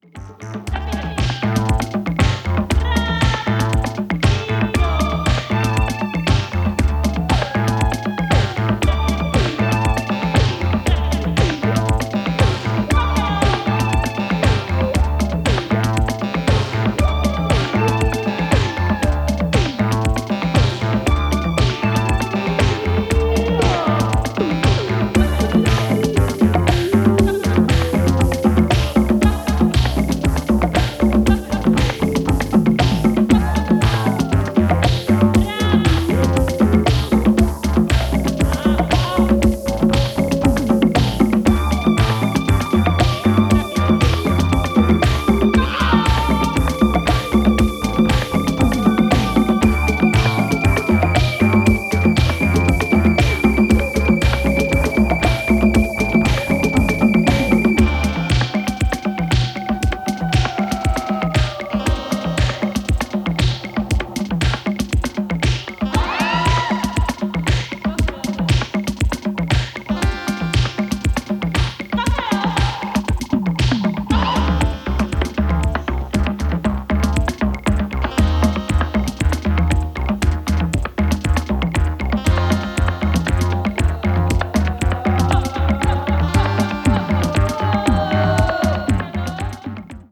no-wave, post-disco
Disco Electronix House Wave